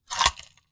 Close Combat Break Bone 6.wav